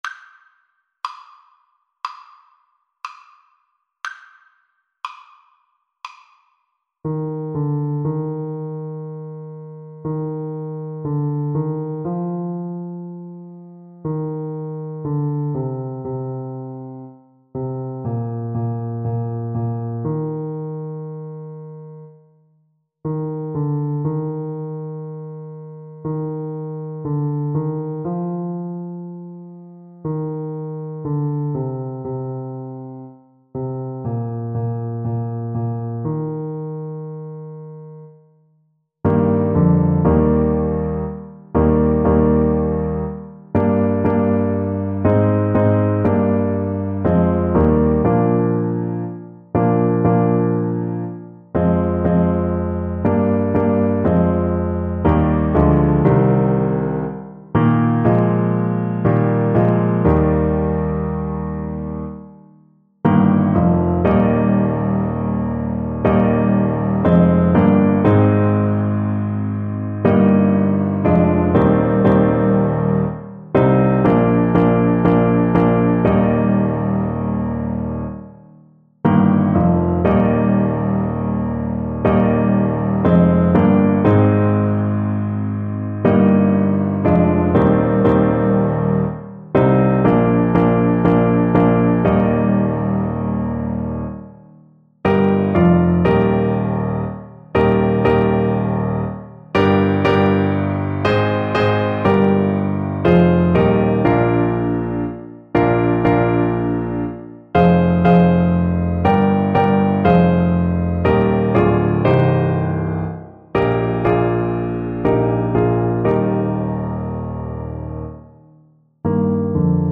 Play (or use space bar on your keyboard) Pause Music Playalong - Piano Accompaniment Playalong Band Accompaniment not yet available transpose reset tempo print settings full screen
Trombone
Namibian traditional song, sometimes sung as part of a funeral procession.
Eb major (Sounding Pitch) (View more Eb major Music for Trombone )
Slow and expressive =c.60